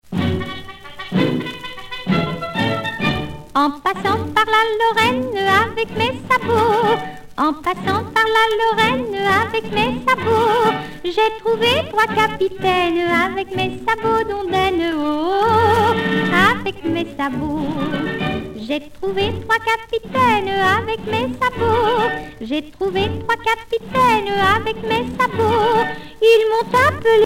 Genre laisse
Pièce musicale éditée